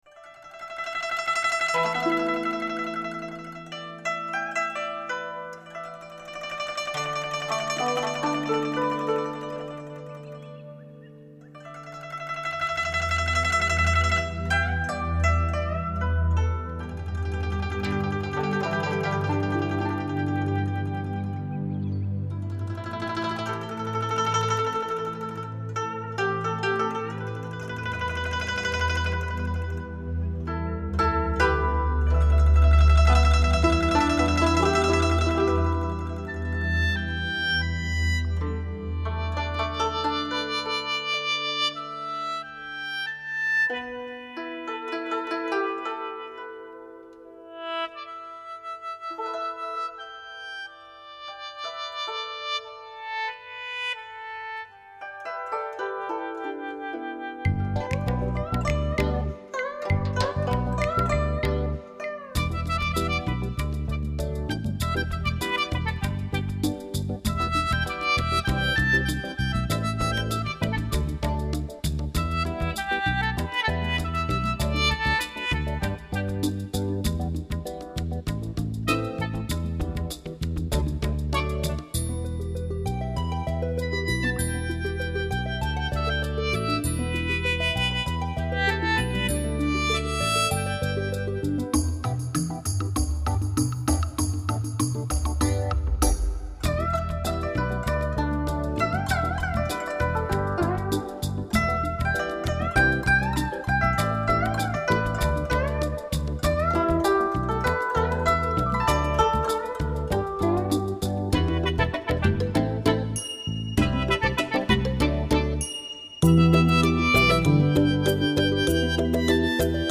专辑类型：纯音乐
本辑是山东民歌改编的轻音乐。 乐队由中国的民族乐器为主奏和领奏，并辅以西洋管弦乐队及适量的电声乐器。